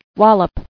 [wal·lop]